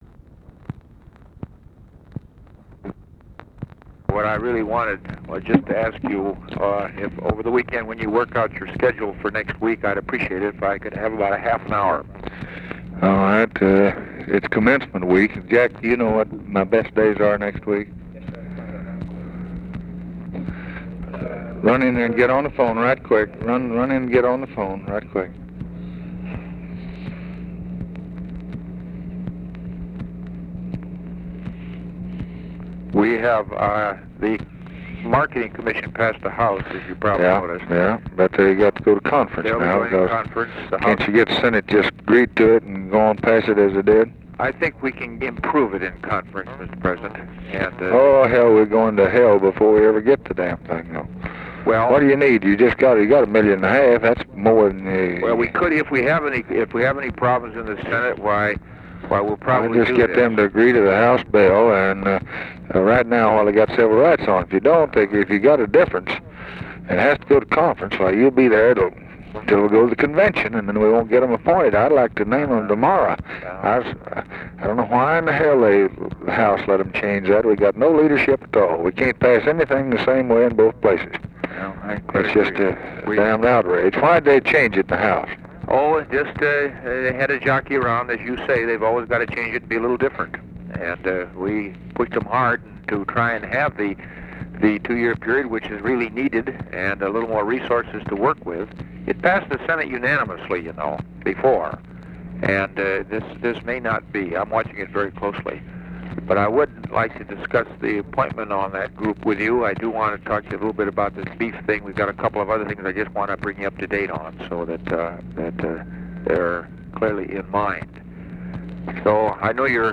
Conversation with ORVILLE FREEMAN and OFFICE CONVERSATION, June 6, 1964
Secret White House Tapes